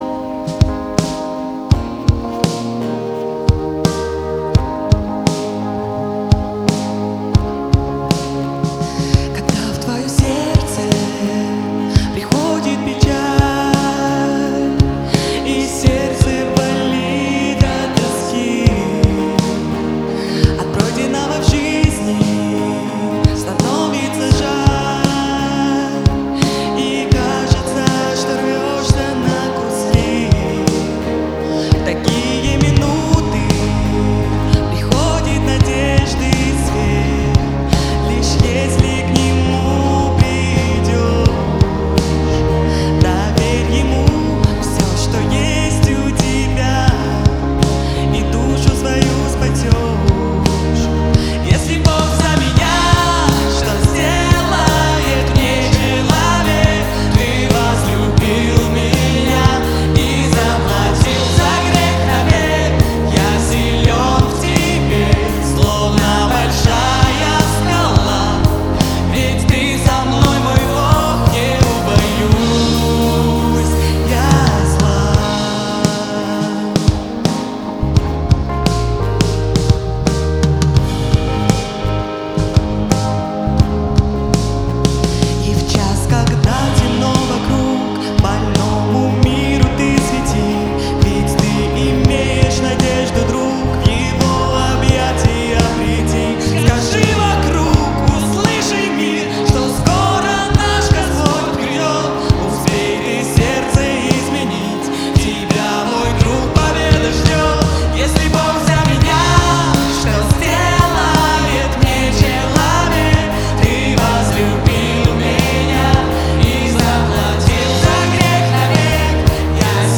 9 просмотров 12 прослушиваний 2 скачивания BPM: 170 4/4